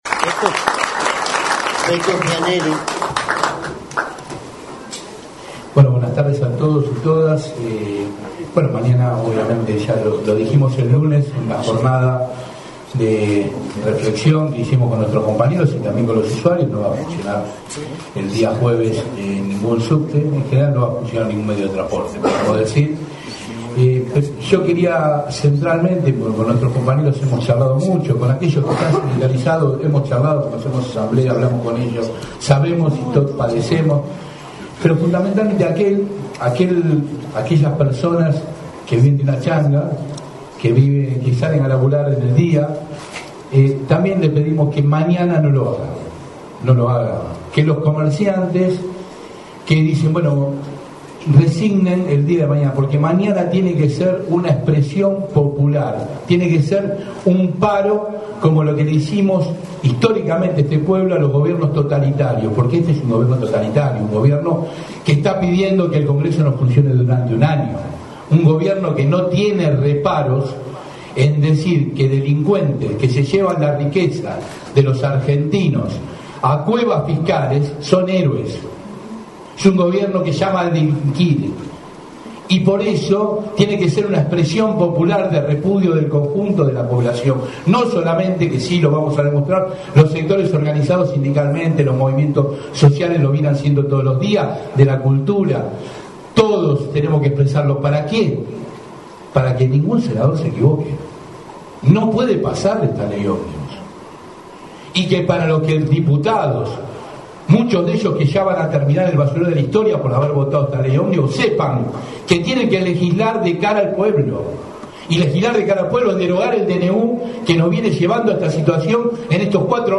Desgrabaciones de las diferentes exposiciones en el marco de la conferencia de prensa realizada en la mañana de hoy, explicando el alcance de la medida de fuerza en repudio a las politicas economicas del gobierno de Javier Milei